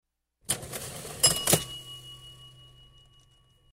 Звуки кассы
7. Приятный звук открытия кассы